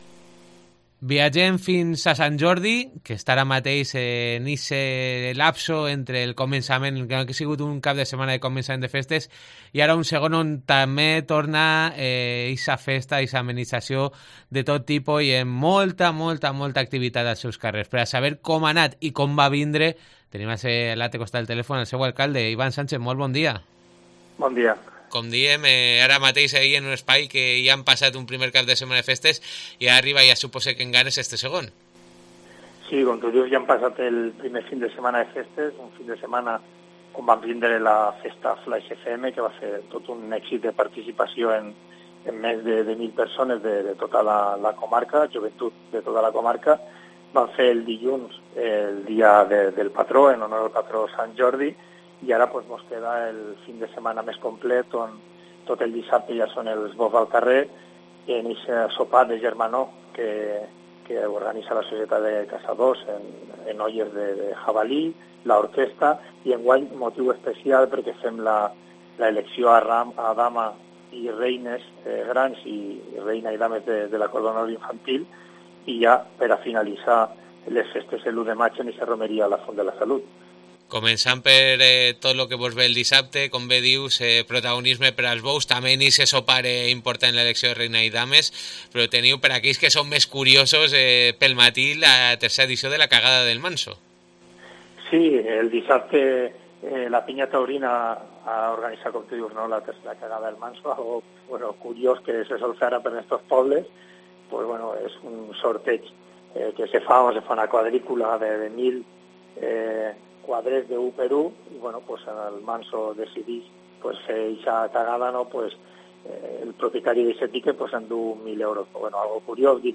Entrevista a Iván Sánchez (alcalde de Sant Jordi)